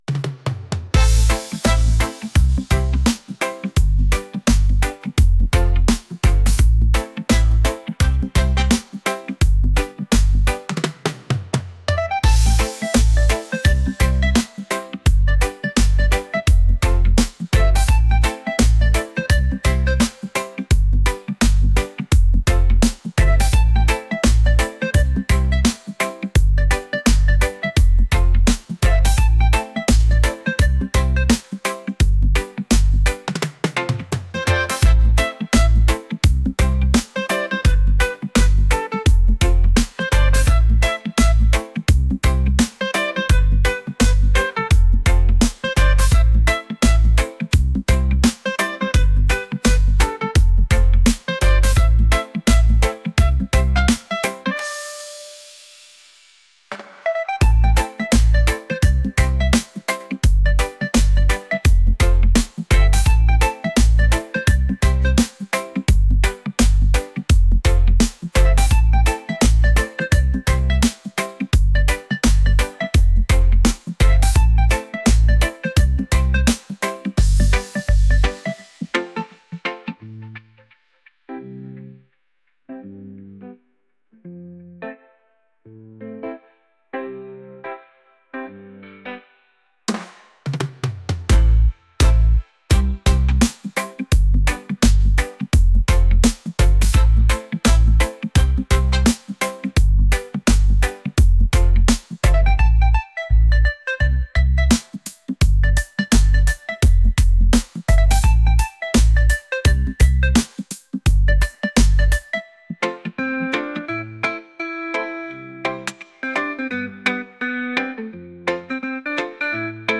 reggae | energetic